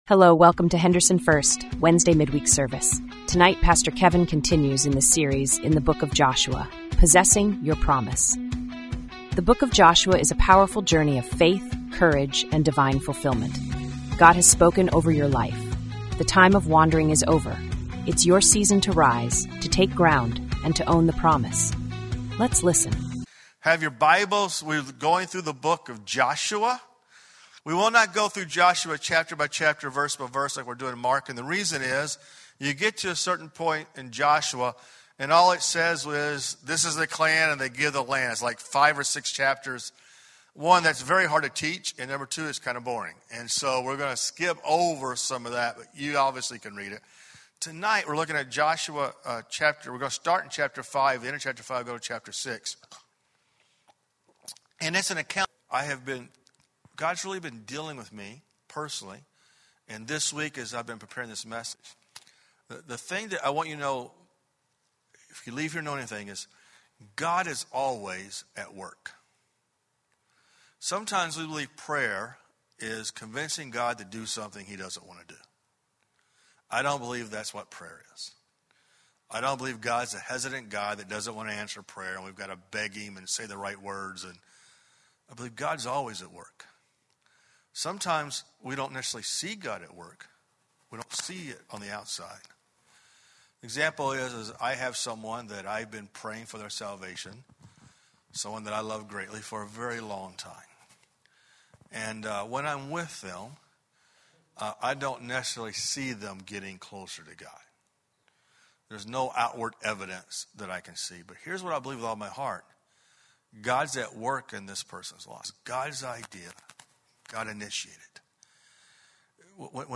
Audio Sermon - Walking with God Daily: Joshua Series, Part 4